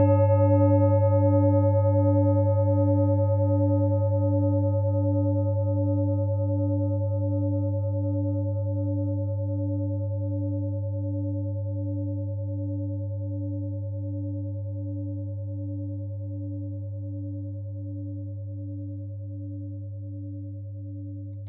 Klangschale Nepal Nr.53
Klangschale-Durchmesser: 24,9cm
(Ermittelt mit dem Filzklöppel)
Die Pi-Frequenz kann man bei 201,06 Hz hören. Sie liegt innerhalb unserer Tonleiter nahe beim "Gis".
klangschale-nepal-53.wav